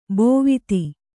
♪ bōviti